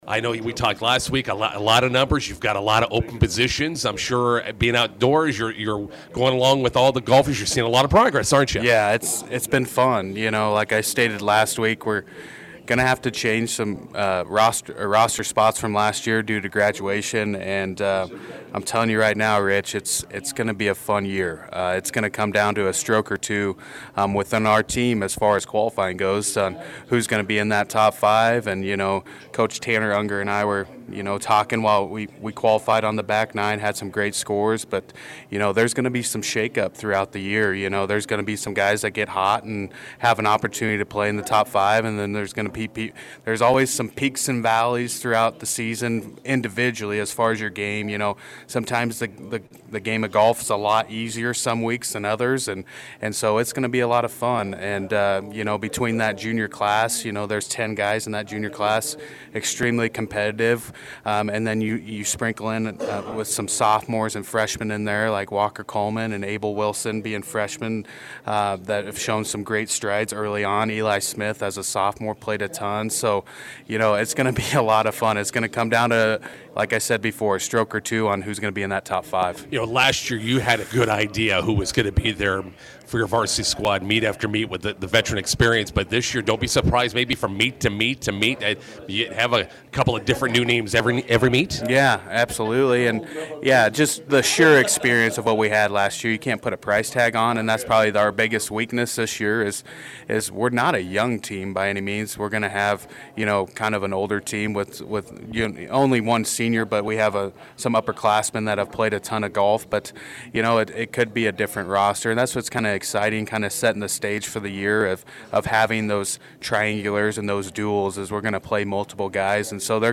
INTERVIEW: Bison golfers open spring season Friday at North Platte Triangular.